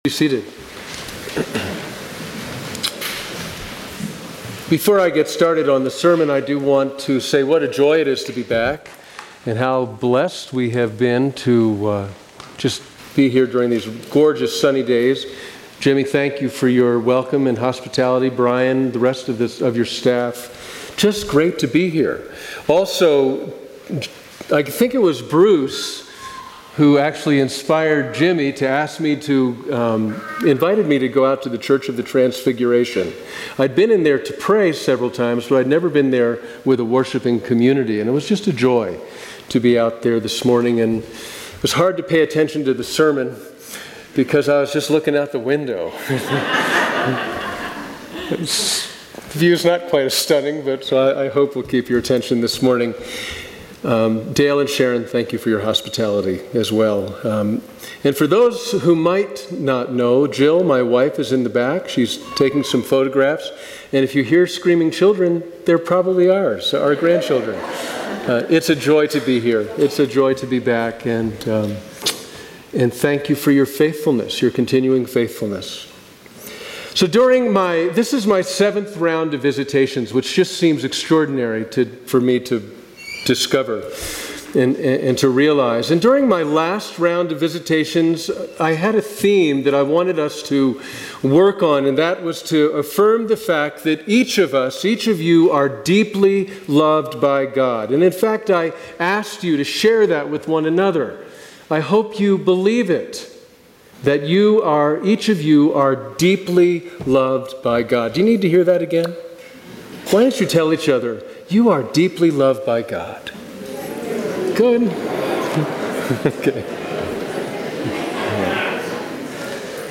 Sermons from St. John's Episcopal Church Confirmation Sunday - Rt.